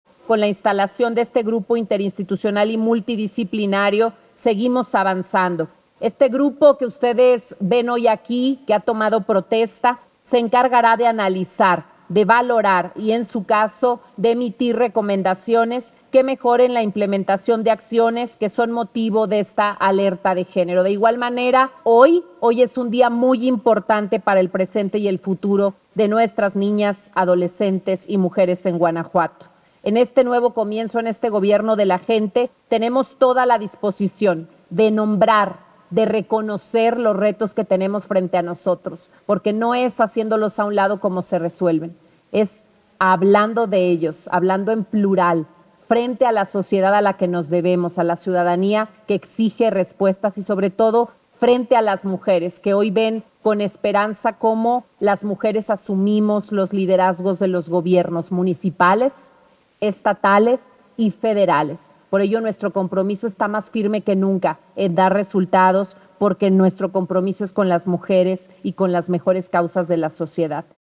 La Gobernadora del Estado de Guanajuato, Libia Dennise García Muñoz Ledo, instruyó al Gabinete Legal y Ampliado la atención puntual de estas medidas y acciones.
mensaje-de-la-Gobernadora-Libia-Dennise-rotocolizacion-instalacion-grupo-Interinstitucional-y-multidiciplinario-gto-.mp3